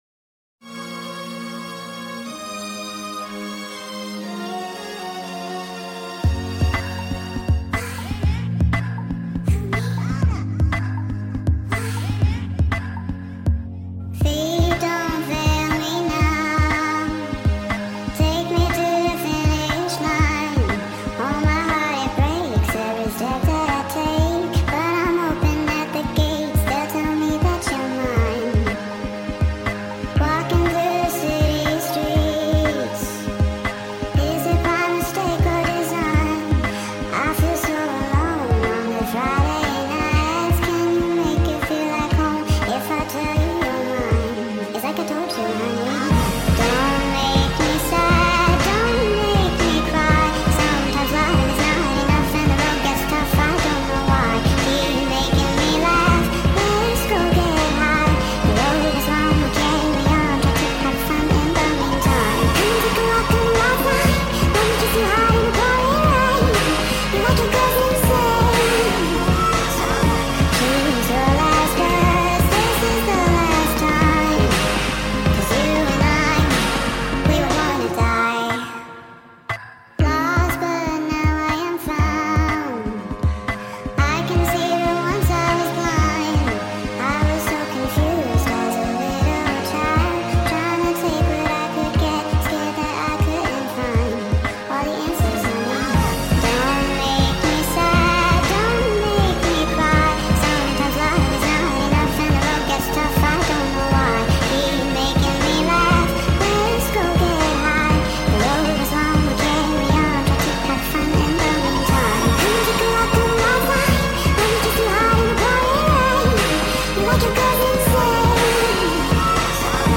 Full Speed Up Song